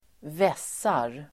Uttal: [²v'es:ar]